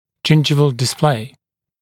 [‘ʤɪnʤɪvəl dɪs’pleɪ] [ʤɪn’ʤaɪvəl][‘джиндживэл дис’плэй] [джин’джайвэл]обнажение десны (видимое), экспозиция десны